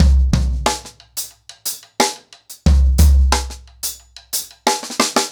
InDaHouse-90BPM.29.wav